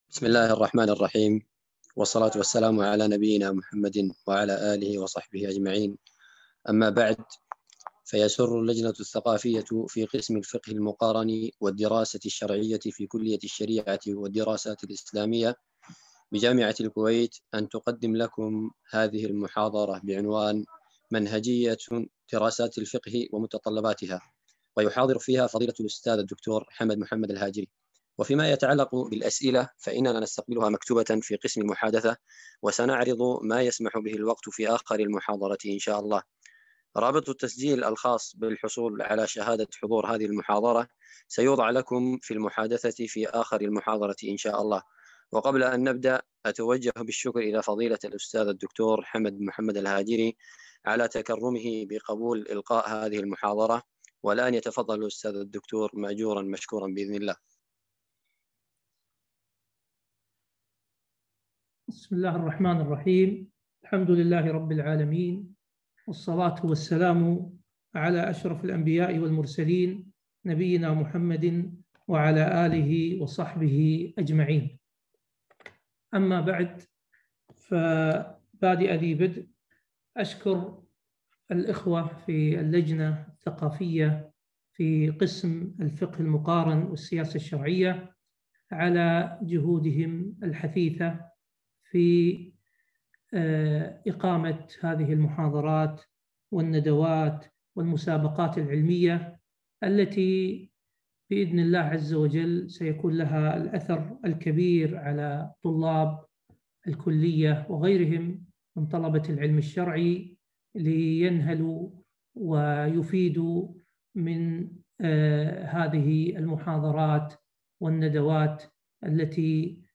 محاضرة - دورة منهجية دراسة الفقه ومتطلباتها